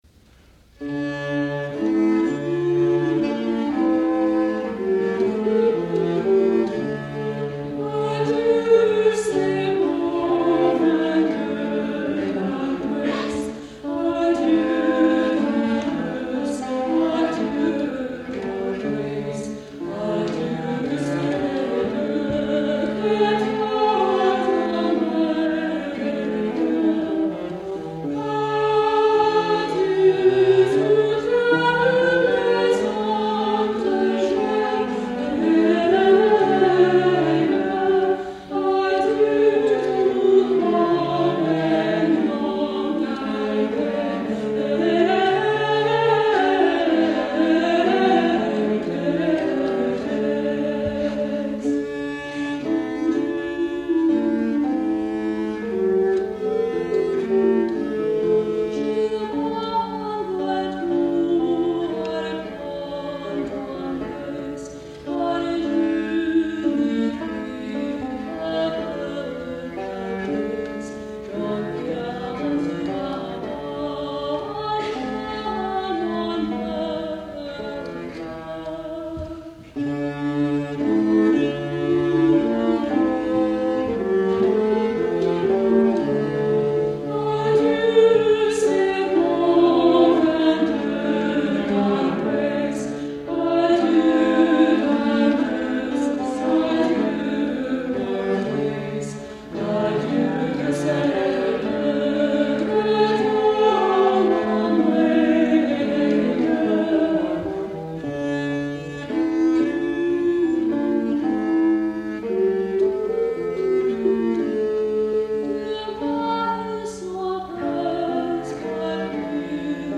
mezzo